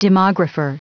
Prononciation du mot demographer en anglais (fichier audio)
Prononciation du mot : demographer